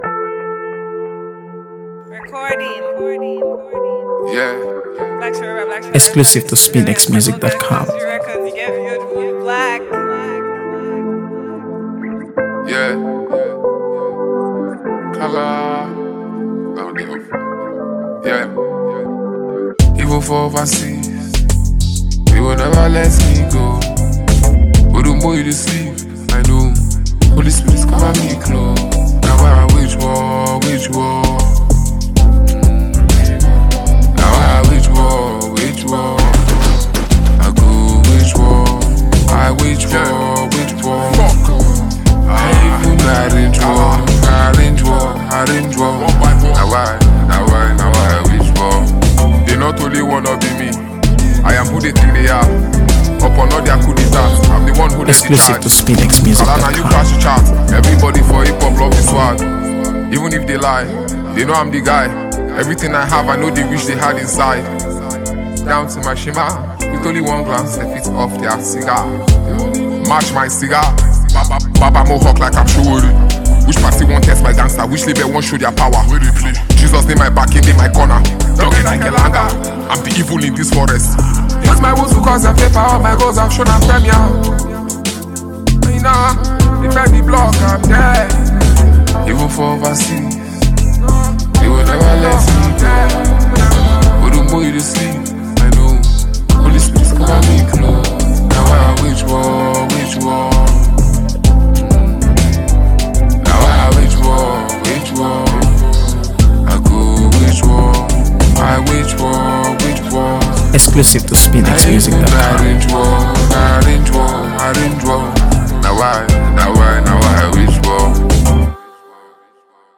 AfroBeats | AfroBeats songs
high-energy track